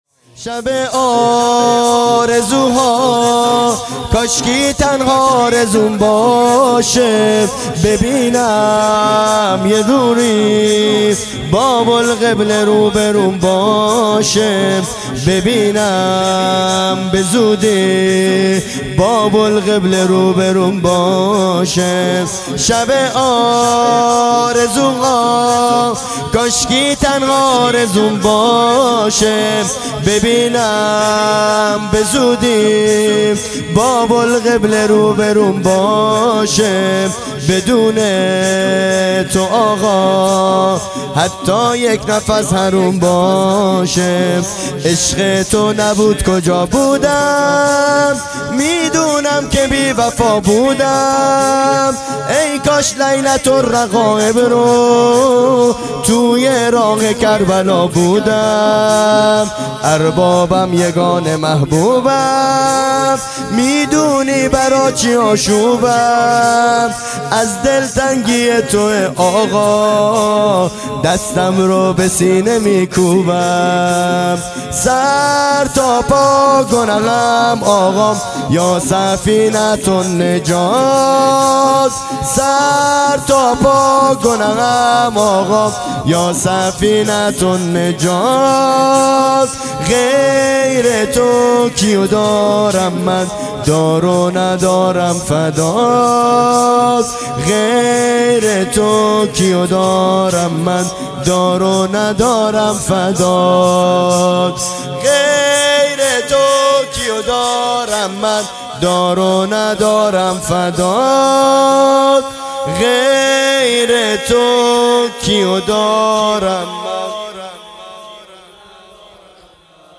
خیمه گاه - هیئت بچه های فاطمه (س) - شور | شب آرزو ها کاشکی تنها آرزوم باشه | پنجشنبه ۳۰ بهمن ۹۹